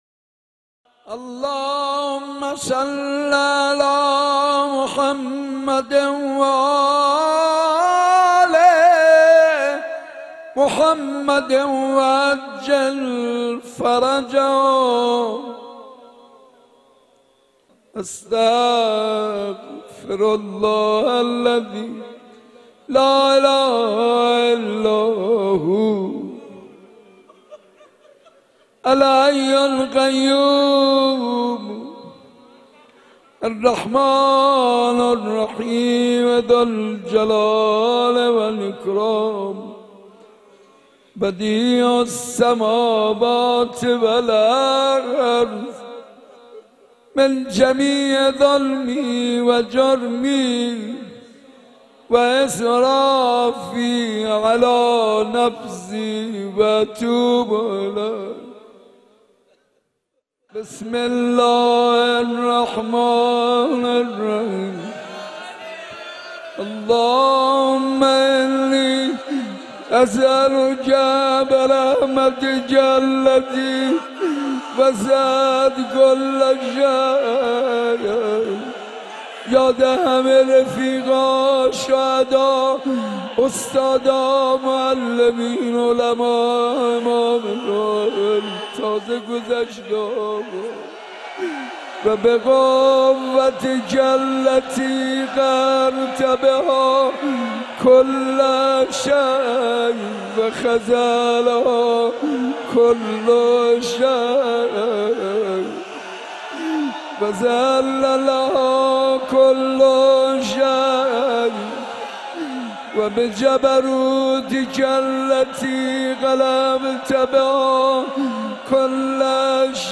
مراسم شب نهم ماه مبارک رمضان با سخنرانی حجت الاسلام و المسلمین سید حسین حسینی قمی، مناجات خوانی توسط حاج منصور ارضی و مداحی حاج حسین سازور در مسجد ارک برگزار شد.